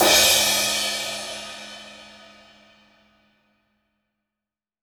Index of /90_sSampleCDs/AKAI S6000 CD-ROM - Volume 3/Drum_Kit/ROCK_KIT2